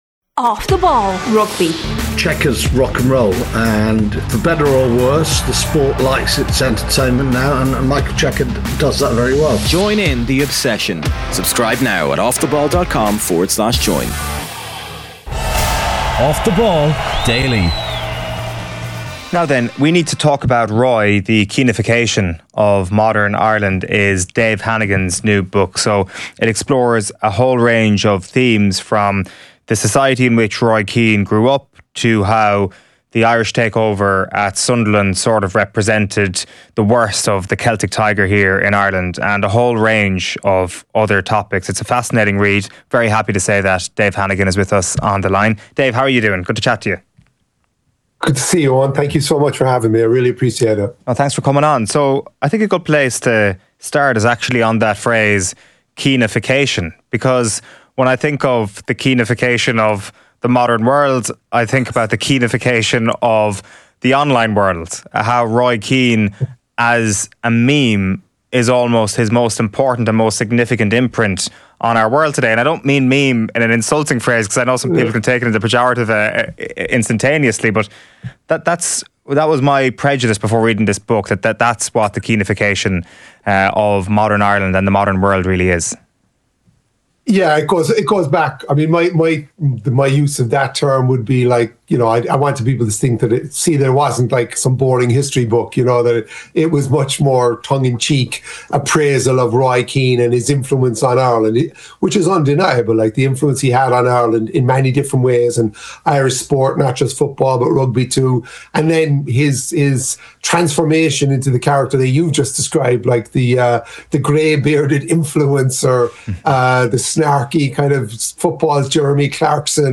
co-hosts